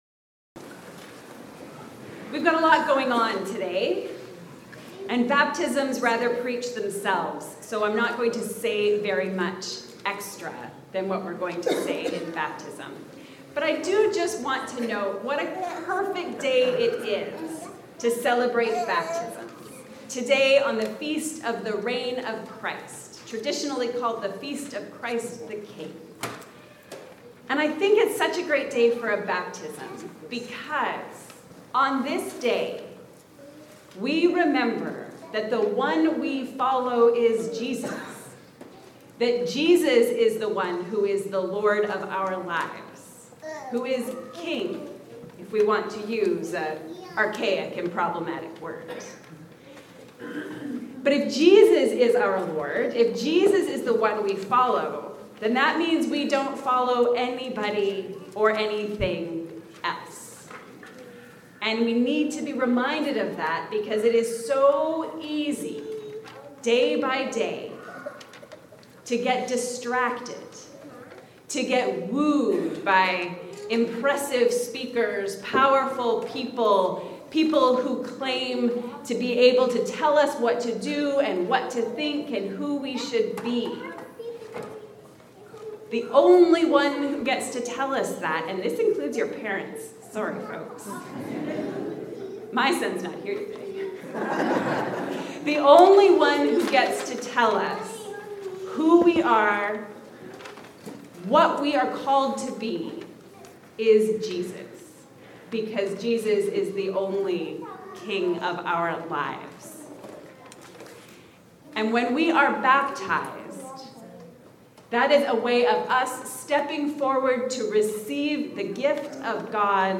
We follow Jesus. A mini-sermon for the celebration of baptisms on the Reign of Christ.